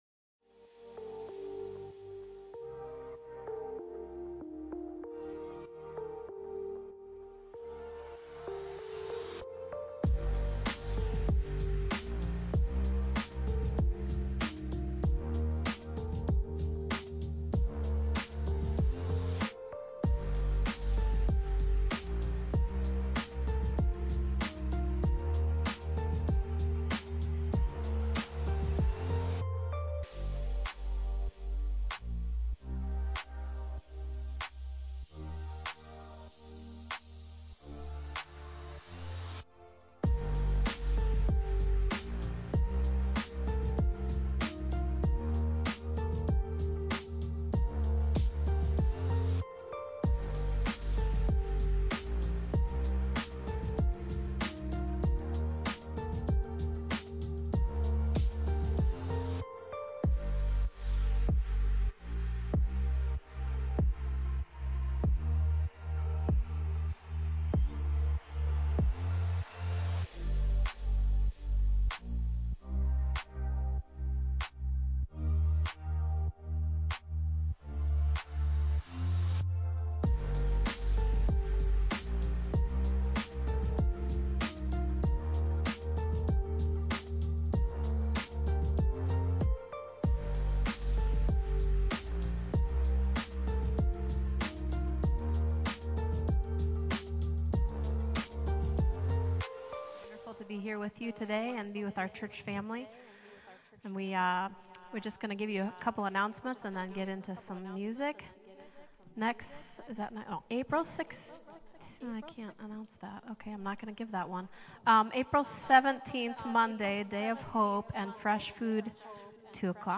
Our fantastic worship team leads us as we praise God on this beautiful Easter Sunday.
Praise Worship
Welcome with announcements